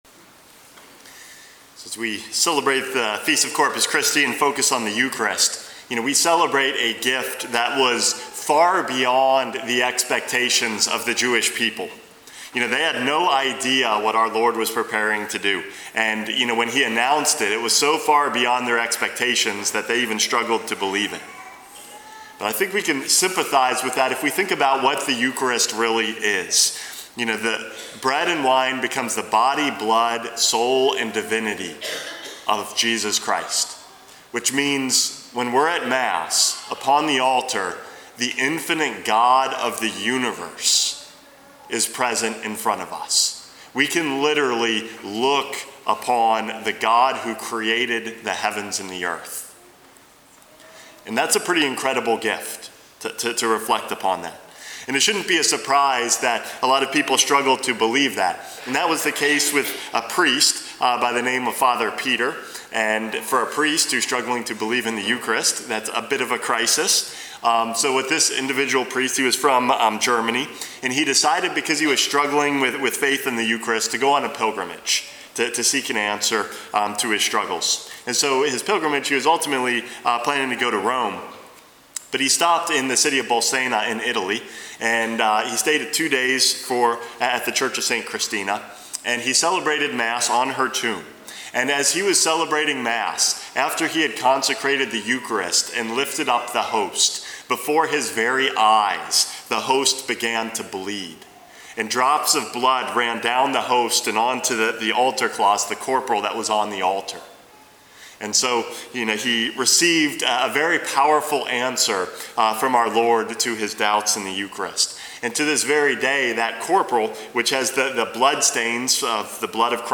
Homily #455 - Great Expectations